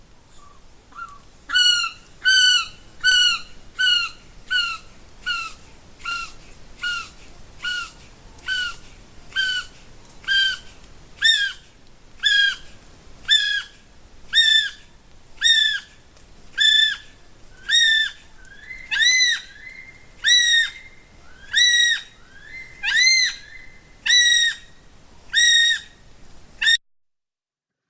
Звуки птицы киви
На этой странице собраны звуки птицы киви — уникальной нелетающей птицы из Новой Зеландии. Вы можете слушать онлайн или скачать её голоса в формате mp3: от нежных щебетаний до характерных криков.